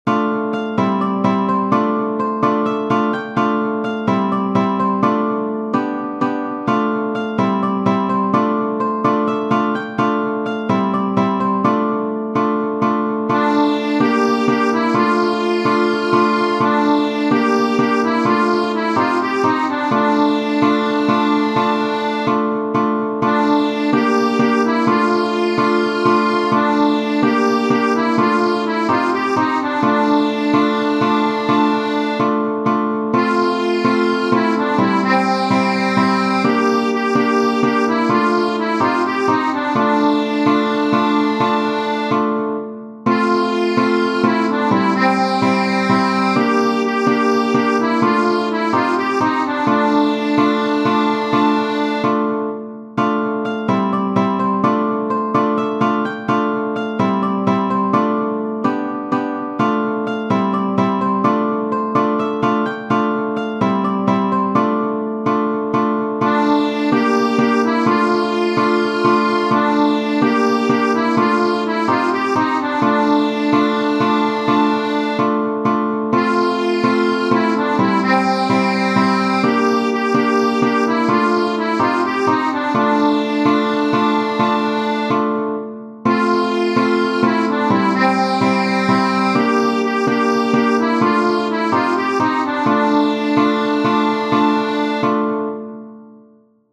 Tradizionale Genere: Folk Testo originale bulgaro di anonimo (Traslitterazione) Domakine, sipi vino da pieme da pieme, domakine, da se napieme.